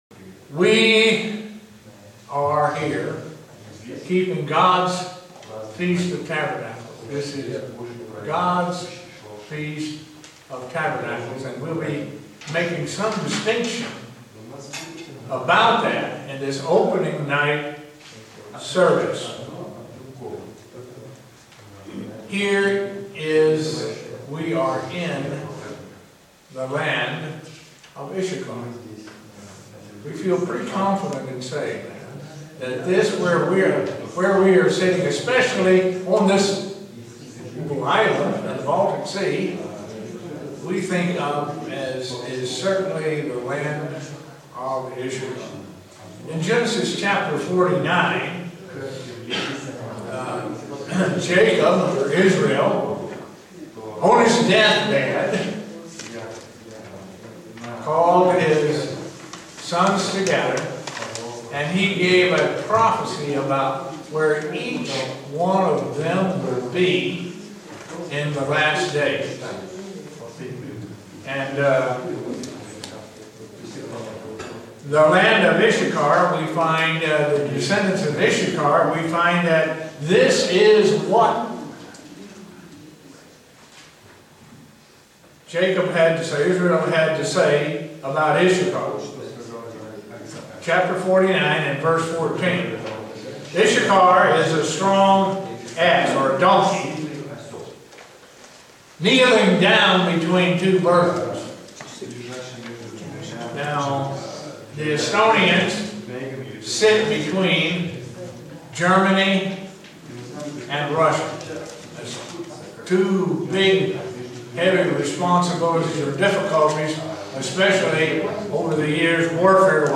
Print What the Feast of Tabernacles is and what it is not. sermon Studying the bible?